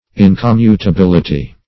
Incommutability \In`com*mu`ta*bil"i*ty\, n.